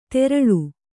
♪ teraḷu